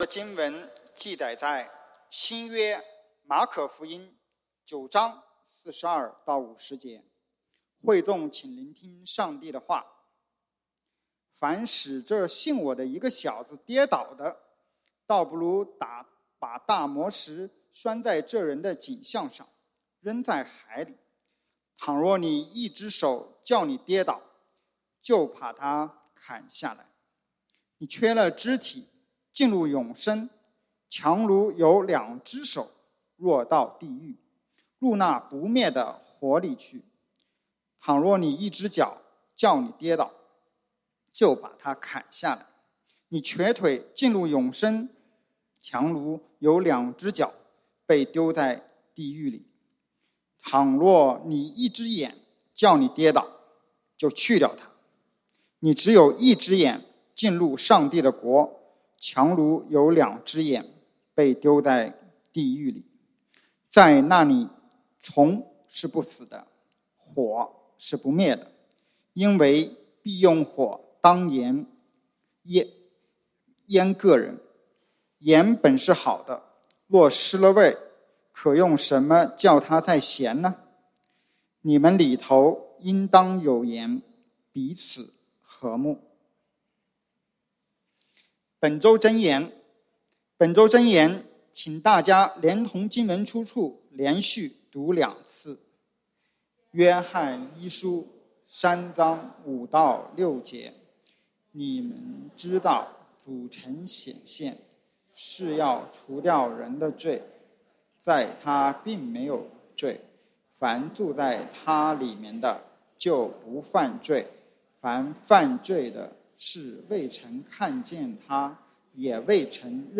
9/26/2021 講道經文：《馬可福音》Mark 9:42-50 本週箴言：《約翰一書》1 John 3:5-6 你們知道主曾顯現，是要除掉人的罪，在祂並沒有罪。